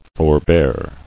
[for·bear]